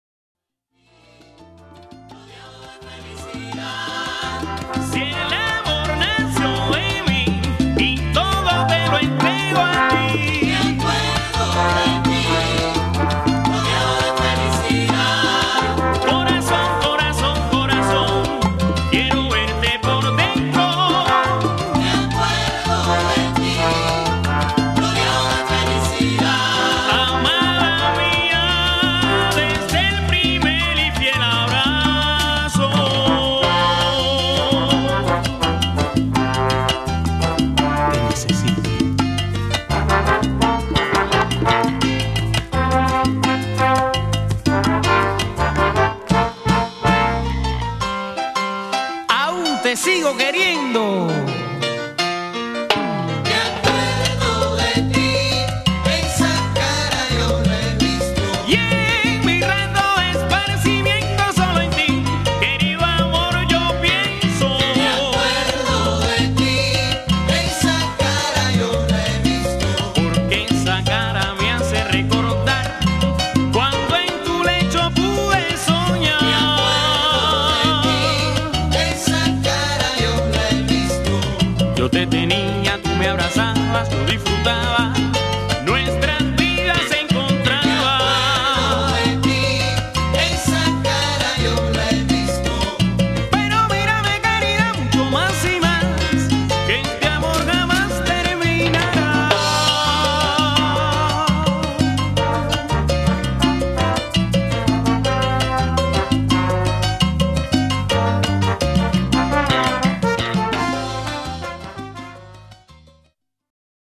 キューバのSALSA / TIMBA 系グループ。